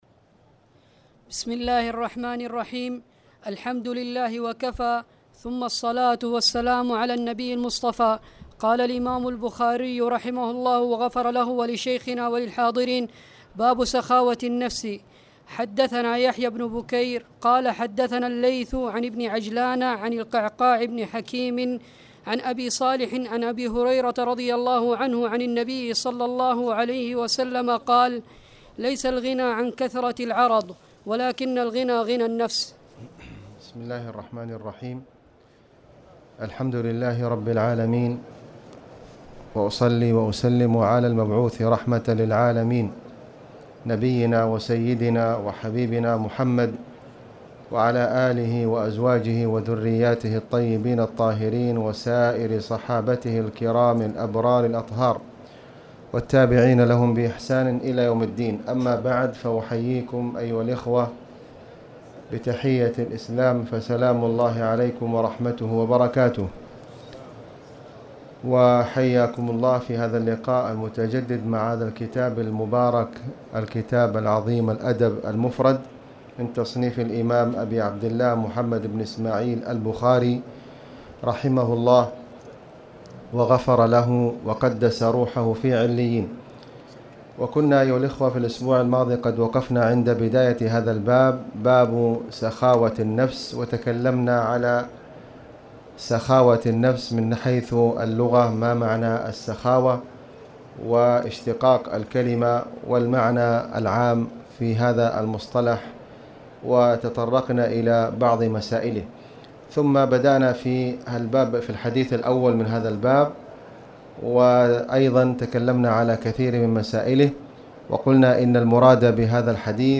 تاريخ النشر ٢١ جمادى الآخرة ١٤٣٨ هـ المكان: المسجد الحرام الشيخ: خالد بن علي الغامدي خالد بن علي الغامدي باب سخاوة النفس